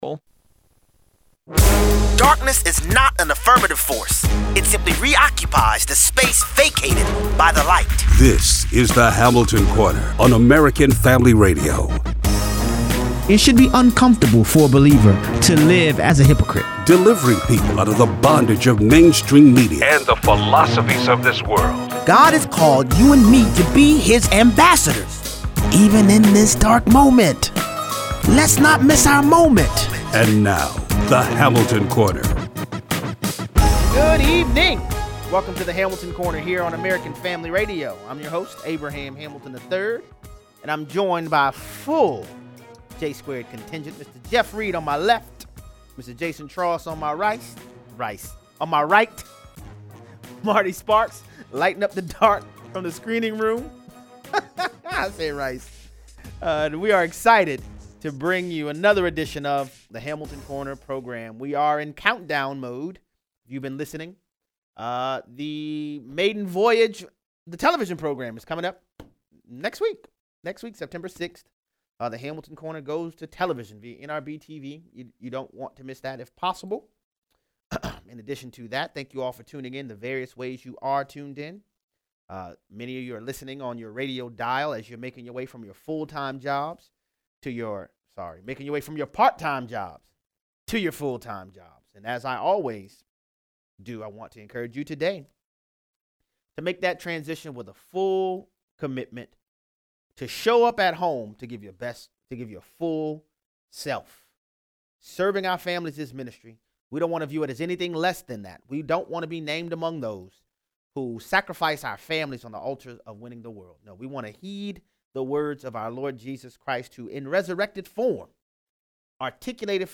Callers weigh in.